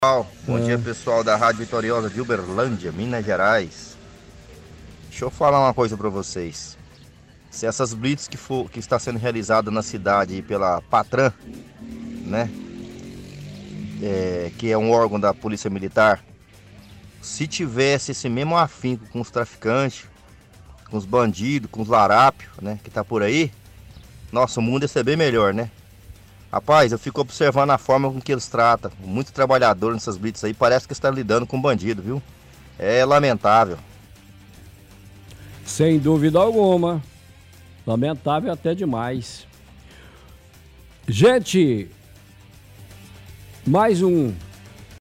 – Ouvinte reclama da quantidade de blitz que estão sendo realizadas na cidade, fala que deveriam focar em criminosos, mas destratam a população em geral.